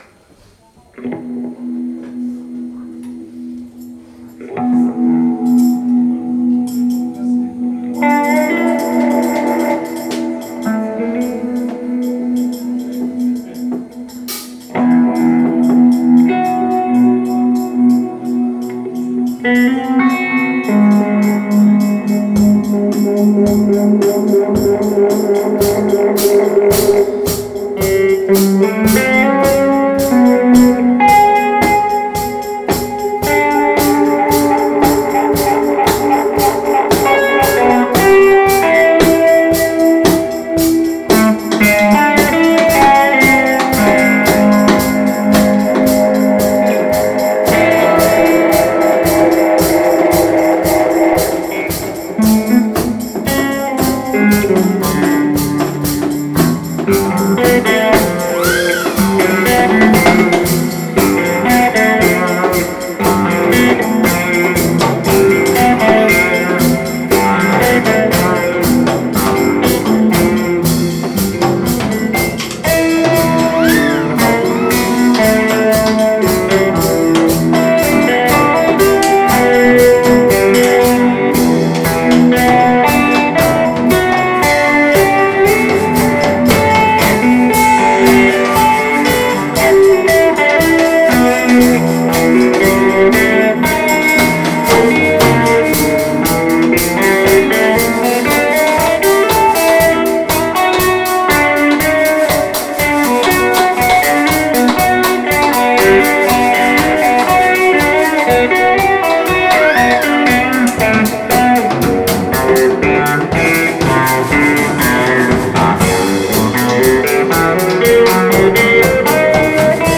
Saxophone/Bassklarinette/Percussion
E-Gitarren
Schlagzeug
Live aufgenommen bei der Soester Jazznacht 07.02.2026